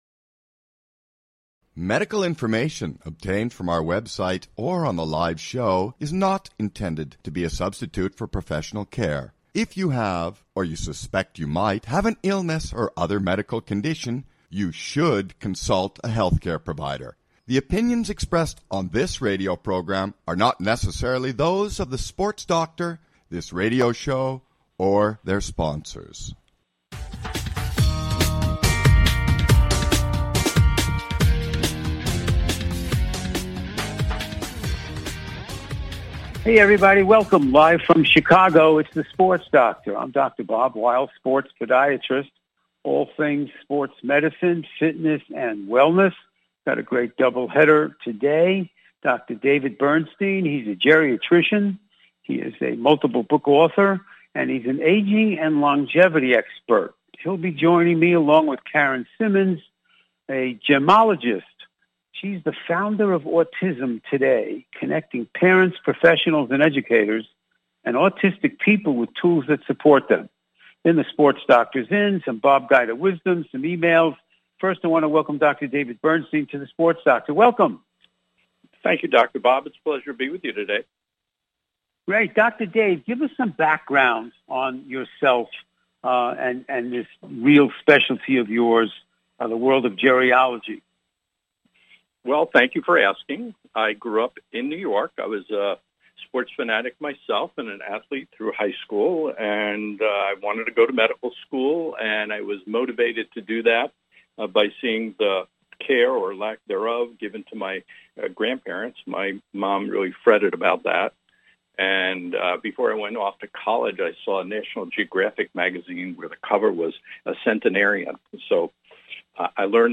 Talk Show Episode, Audio Podcast, The Sports Doctor and Guests